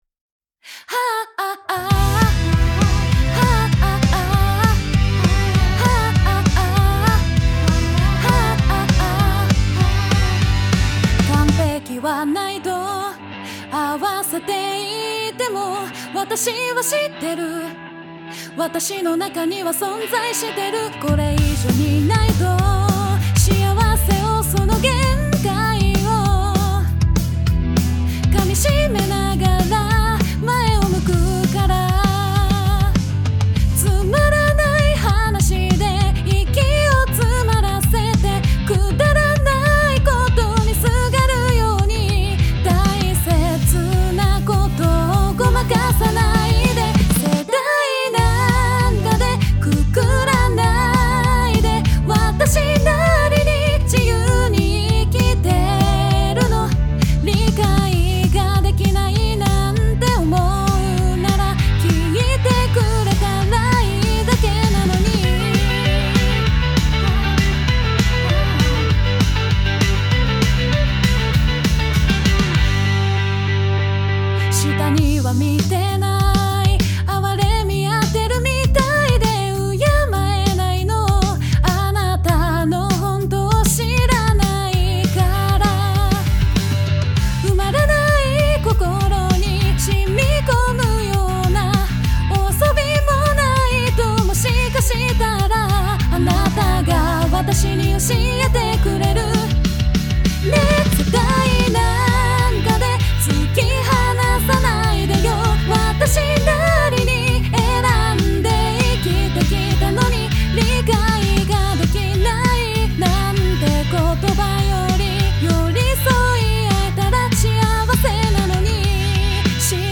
BPM 197
いつも通りのロックになってしまいましたが